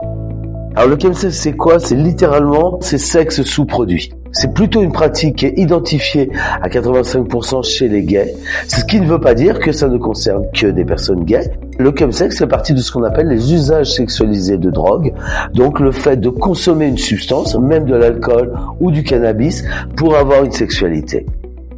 1-chemsex-c-koi-musique.mp3